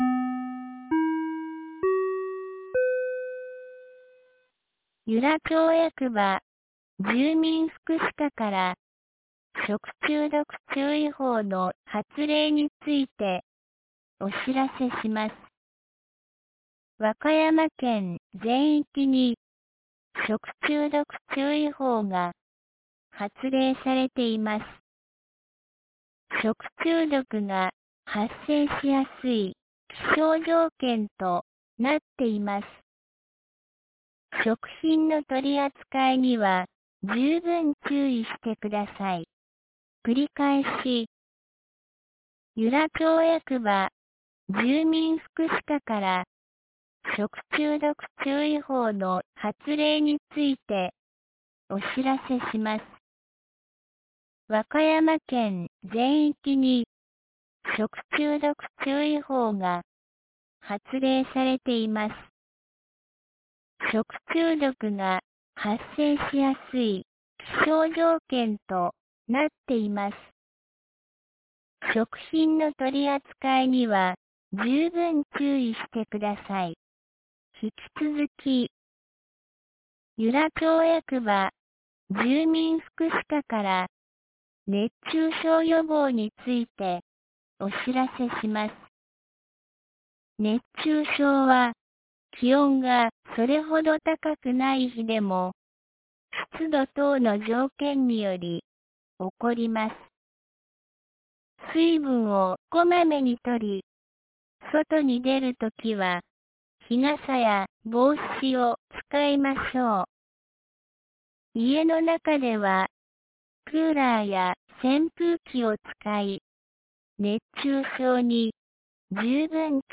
2019年08月11日 12時23分に、由良町より全地区へ放送がありました。
放送音声